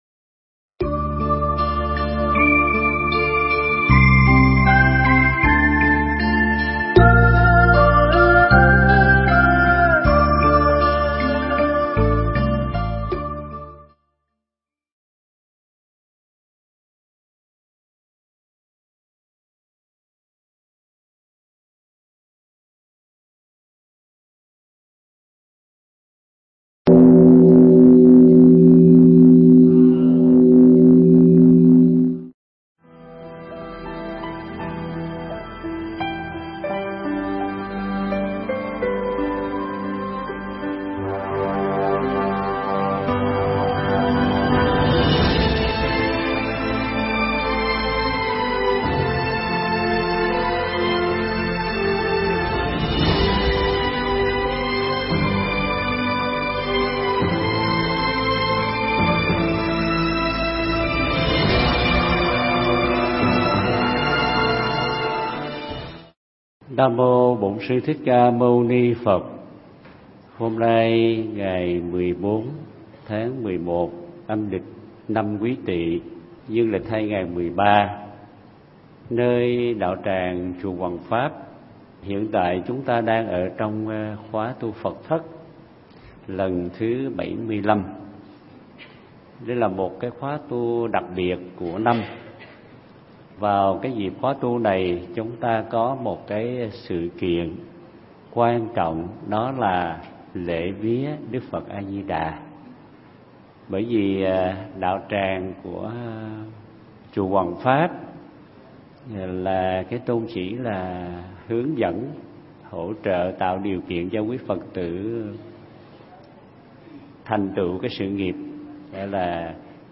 Nghe Mp3 thuyết pháp Chận Dừng Bánh Xe Nhân Quả